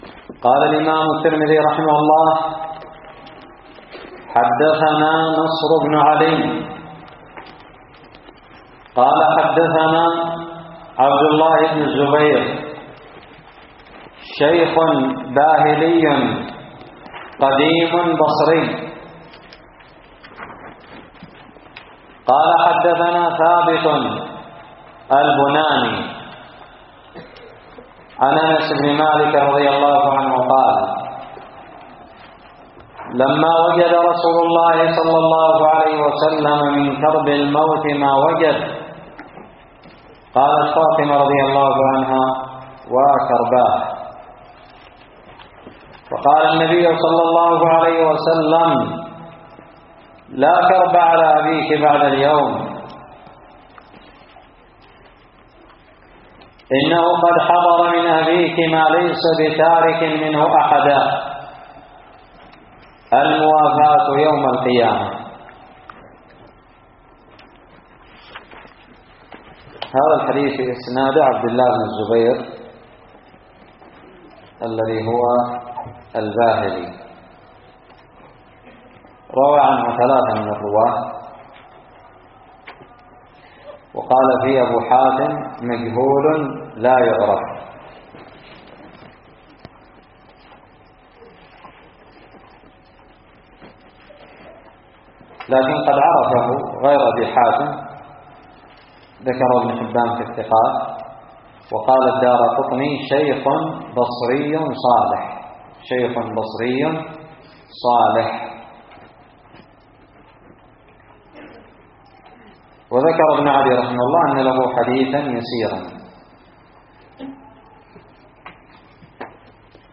الدرس الحادي والثلاثون بعد المائة من شرح كتاب الشمائل المحمدية
ألقيت بدار الحديث السلفية للعلوم الشرعية بالضالع